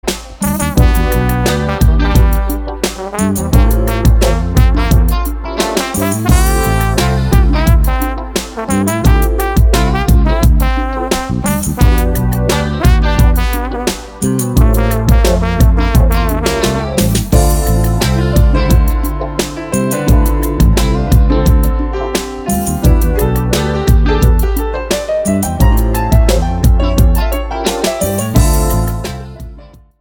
EASY LISTENING  (02.08)